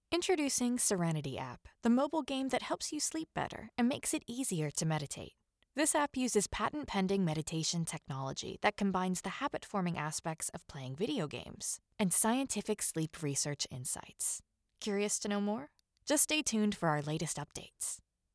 Female
Approachable, Confident, Conversational, Friendly, Reassuring, Upbeat, Warm
East Coast American (native), transatlantic
Peter Pan Audiobook Sample.mp3
Microphone: Sennheiser mkh416